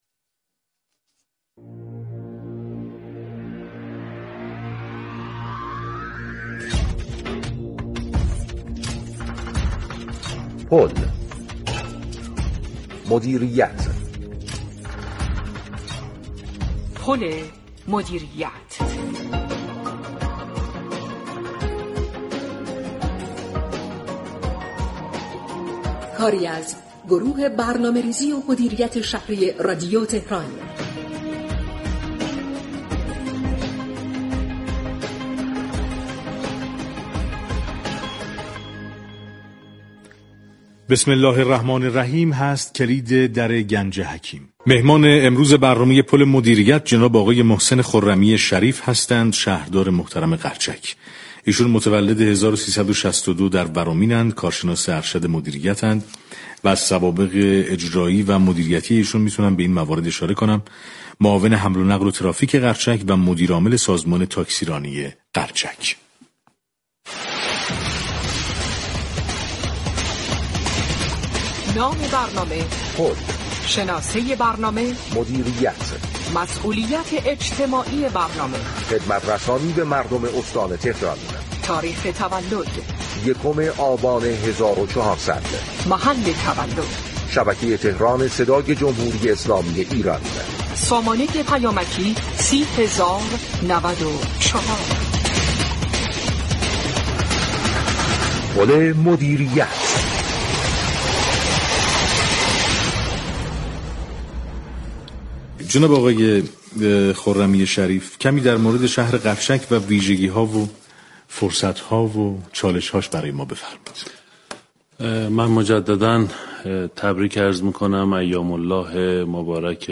به گزارش پایگاه اطلاع رسانی رادیو تهران؛ محسن خرمی شریف شهردار قرچك روز چهارشنبه 12 بهمن همزمان با اولین روز از دهه فجر در استودیوی پخش زنده رادیو تهران حضور یافت و با برنامه پل مدیریت گفت و گو كرد.